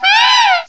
cry_not_liepard.aif